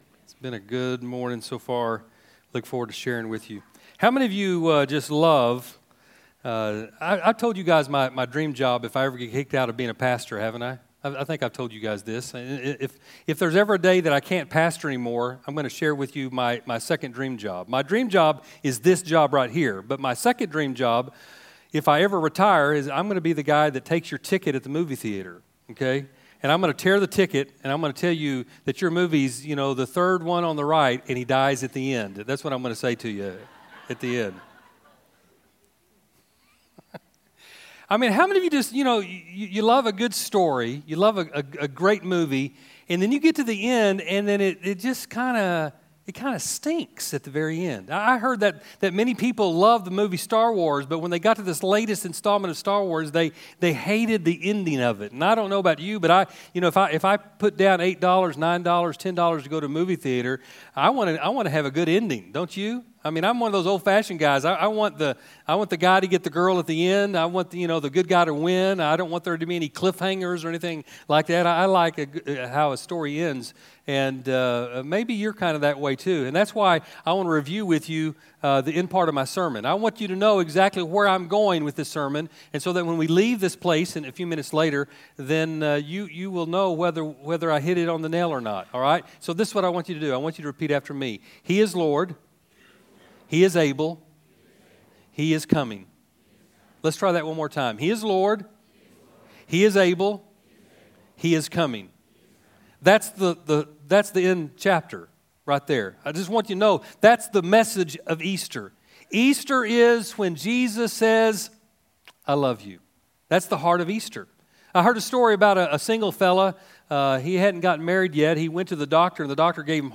Our at home edition of Easter at Central.